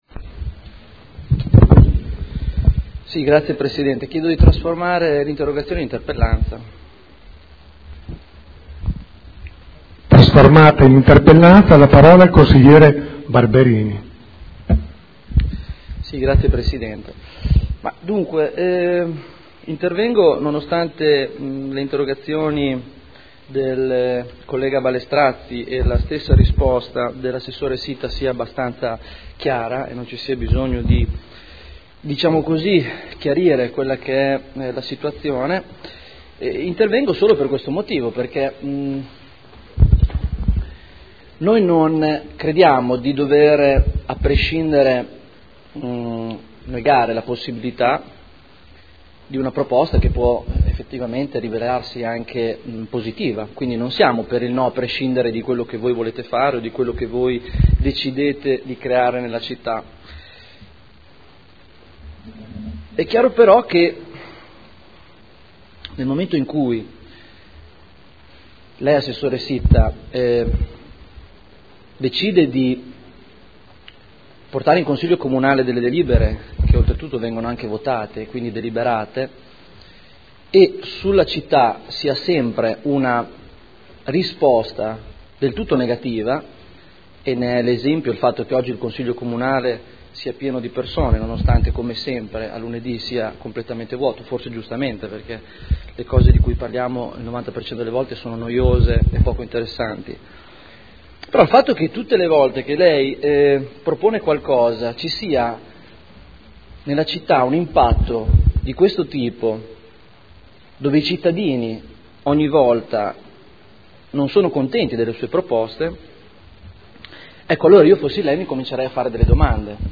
Seduta del 05/09/2011.